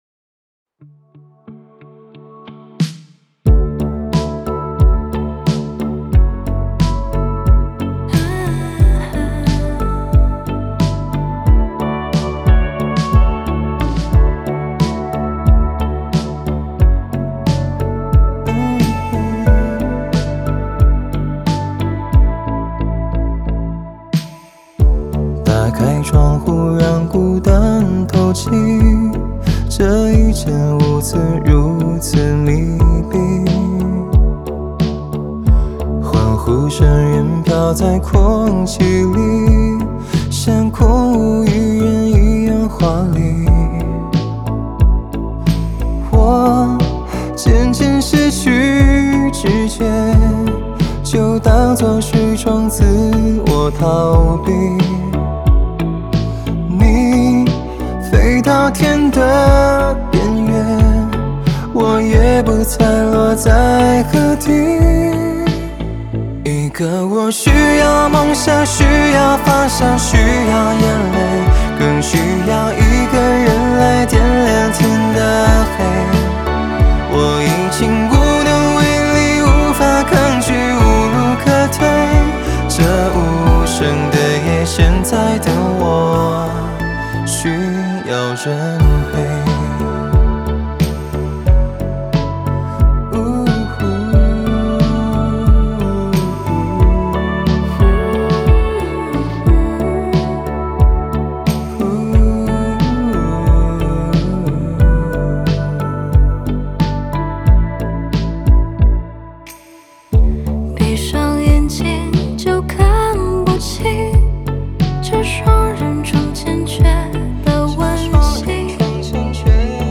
吉他Guitar
键盘Keyboard
贝斯Bass
鼓Drums